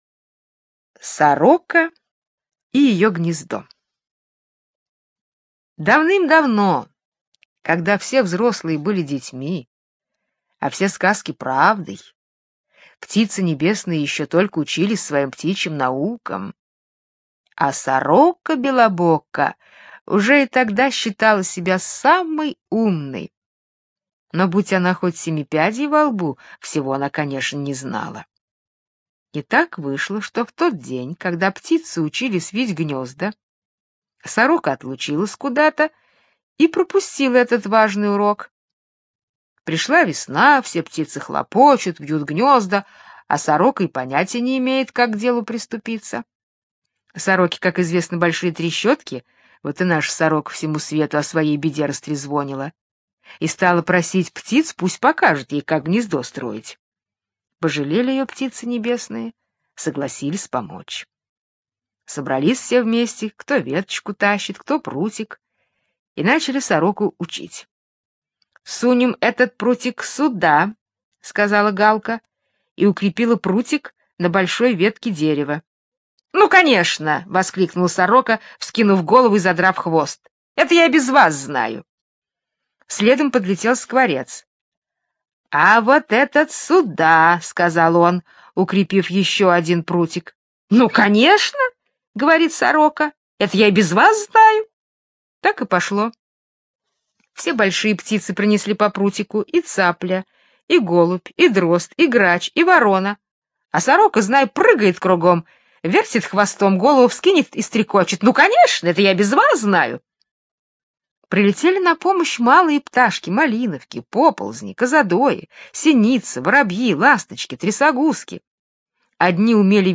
Сорока и ее гнездо - британская аудиосказка - слушать онлайн